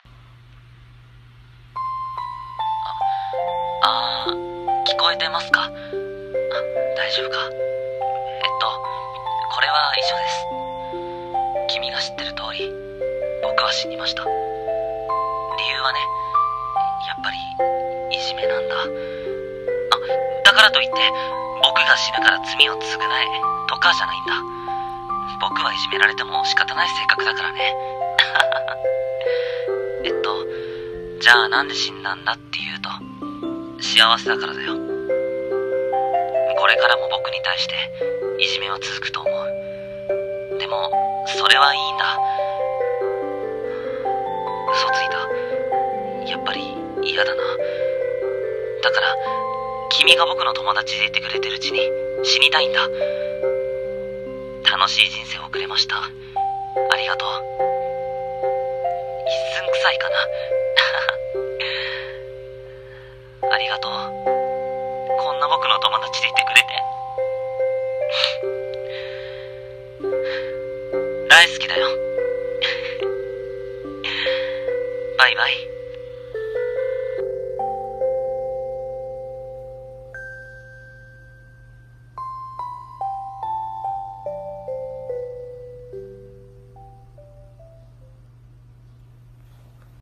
【一人声劇】友達の君に